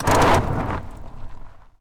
car-brakes-1.ogg